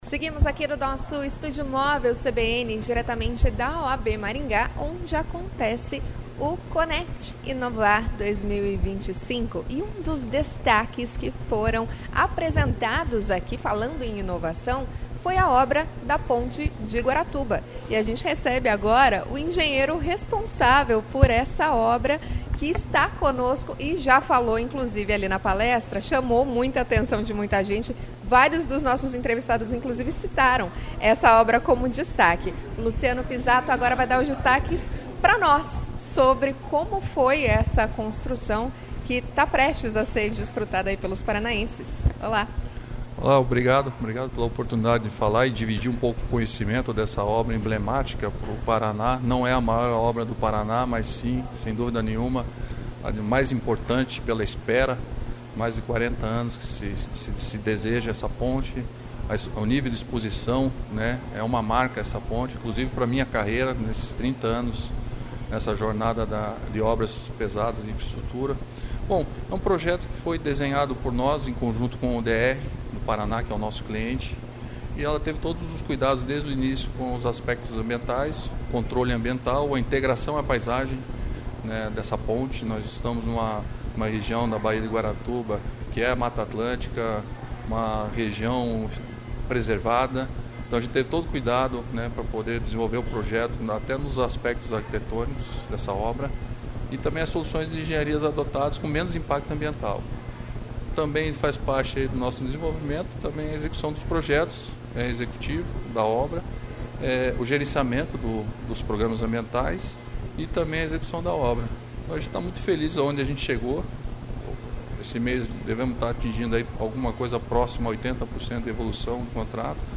Como foi desenhado o projeto? Quais os desafios de execução da obra? Como deve ficar o Paraná depois da entrega da ponte? Ouça a entrevista.
A entrevista foi realizada no Estúdio Móvel da CBN, instalado na sede da OAB Maringá, de onde ocorre a edição do Conecti, que antecede a cerimônia de entrega do Prêmio Sinduscon 2025.